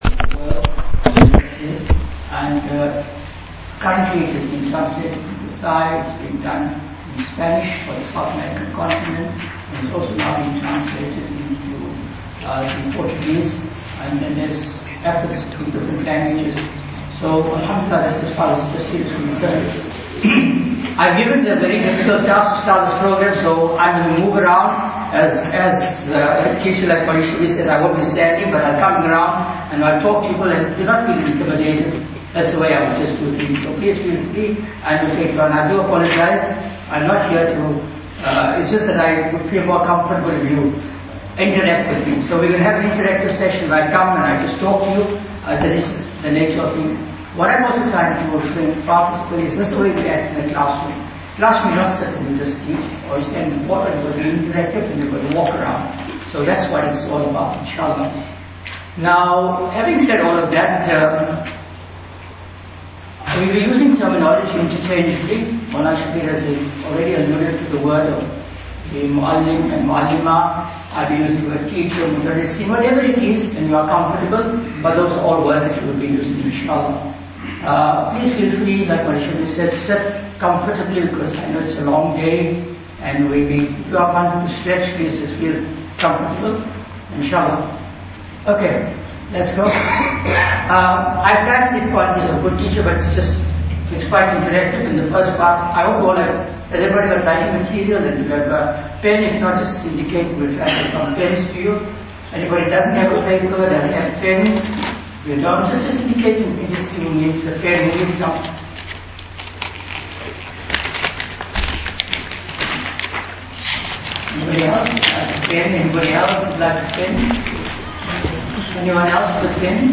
Lecture 1 – Educational Techniques (Saturday 29th April 2017)
Islamic Studies Teachers Professional Development Workshop at Masjid Ibrahim.